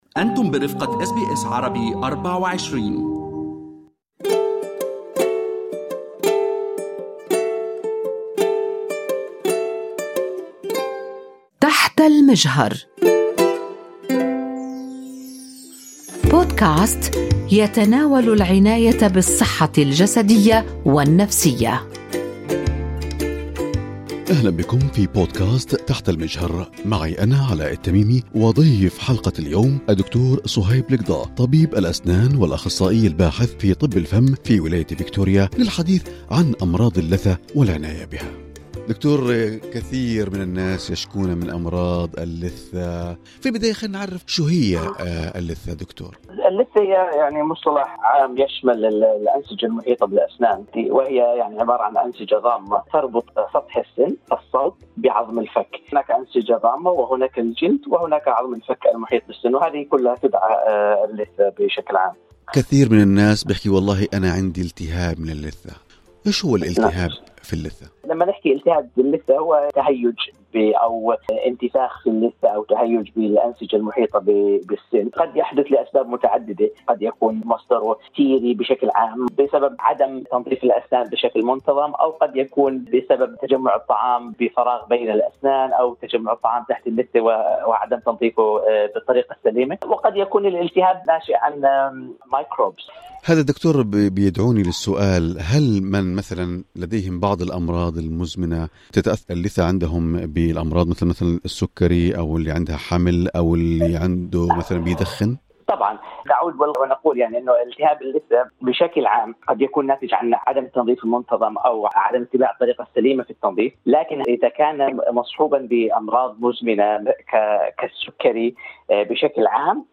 في حديث له مع إذاعة أس بي أس عربي24